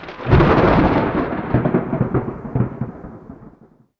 Thunder Crack
A sharp, close thunder crack with bright flash energy and rolling rumble aftermath
thunder-crack.mp3